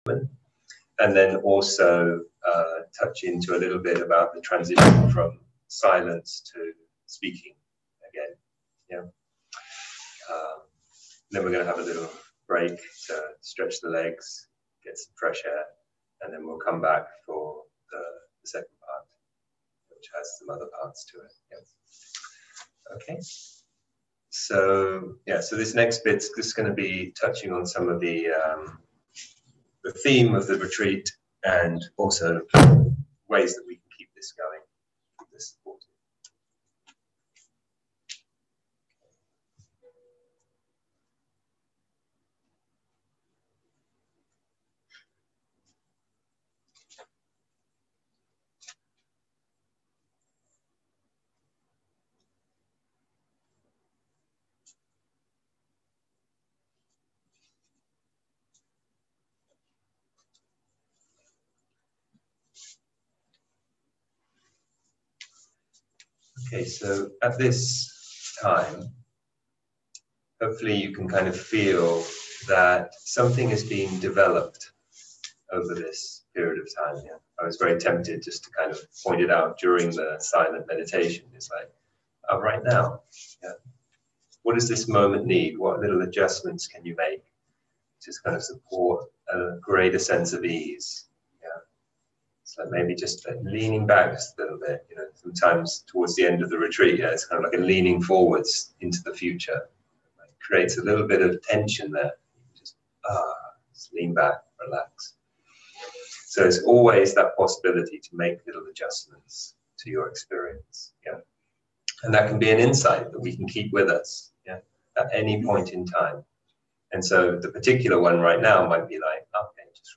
0:00 0:00 סוג ההקלטה: Dharma type: Closing talk שפת ההקלטה: Dharma talk language: English